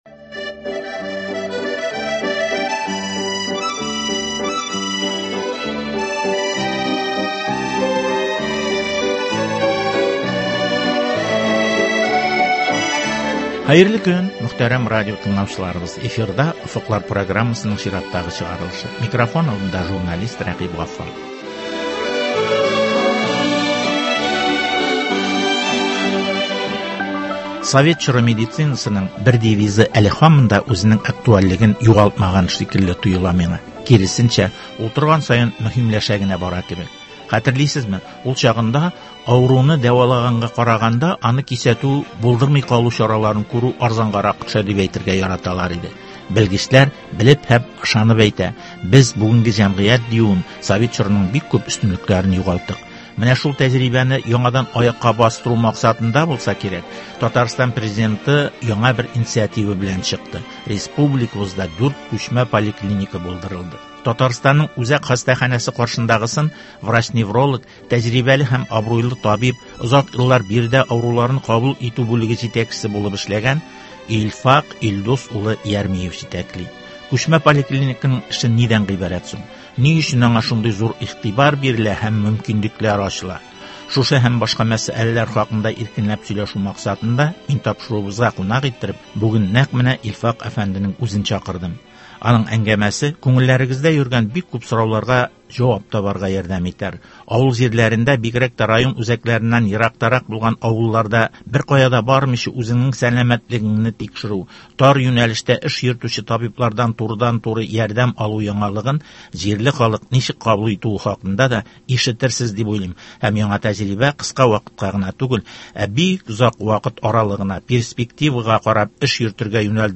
әңгәмә.